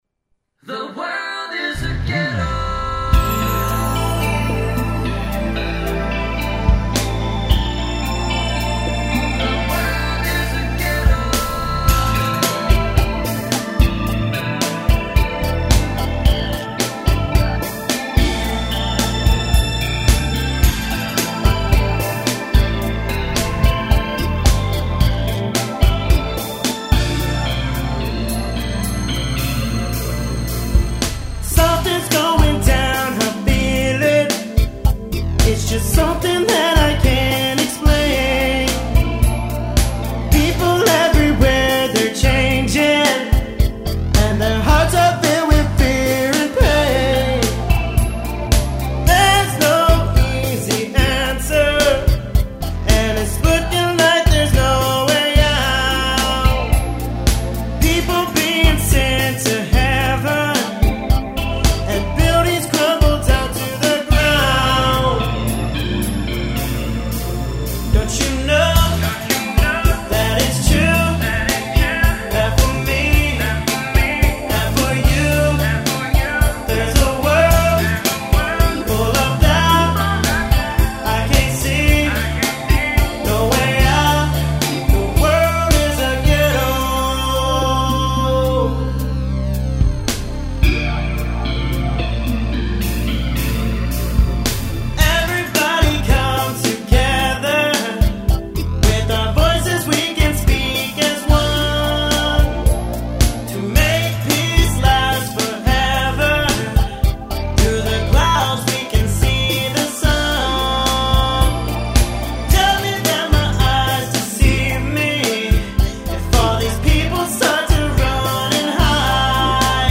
Alternative Rock / Pop